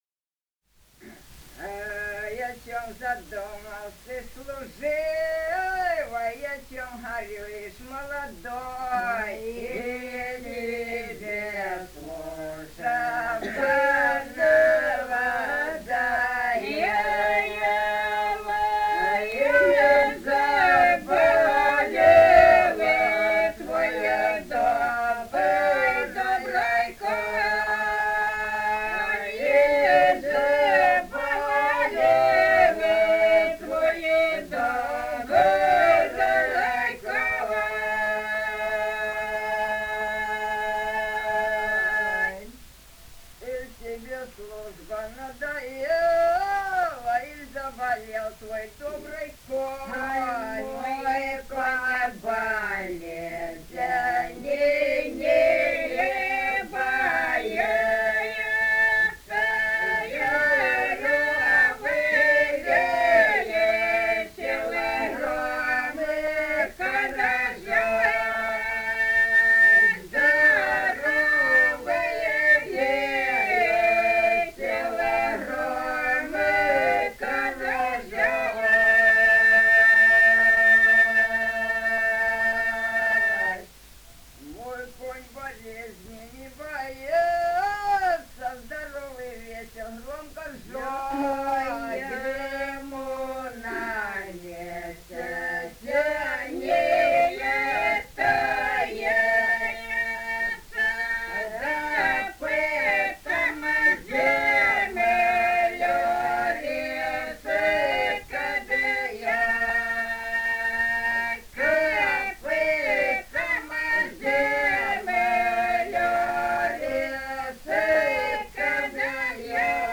полевые материалы
Ростовская область, г. Белая Калитва, 1966 г. И0942-04